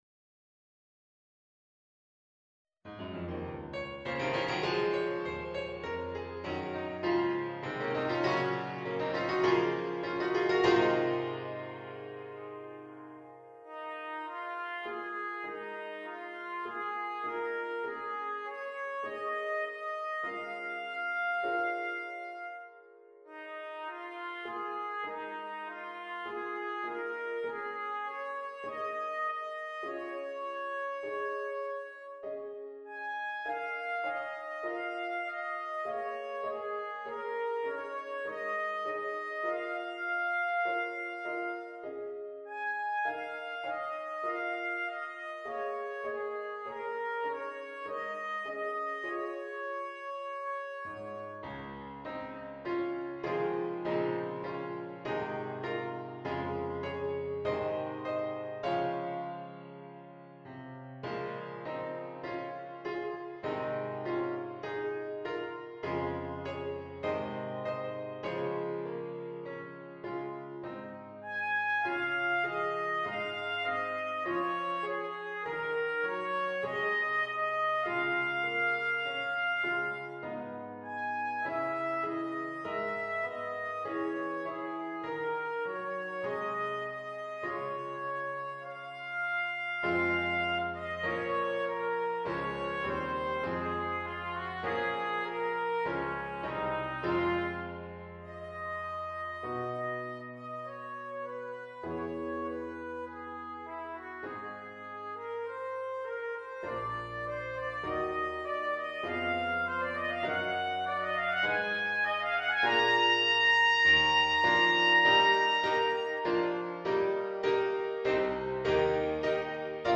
Besetzung: Soprano Eb Cornet Solo & Piano